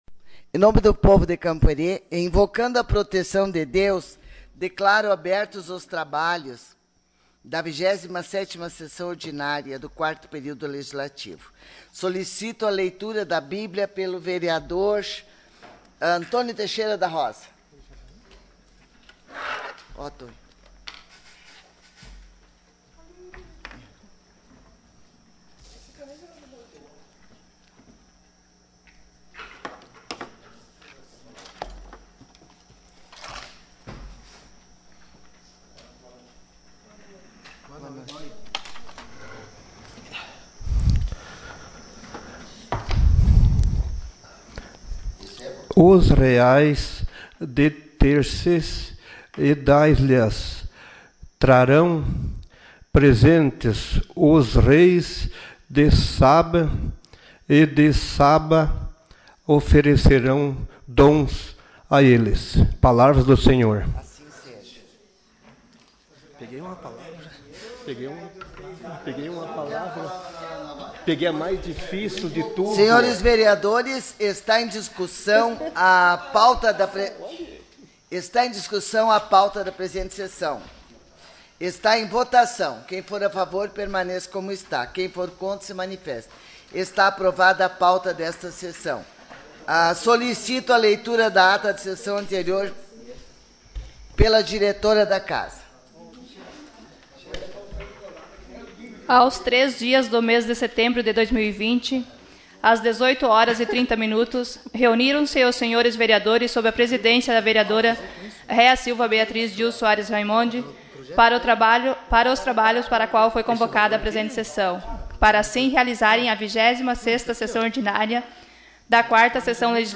Sessão Ordinária dia 10 de setembro de 2020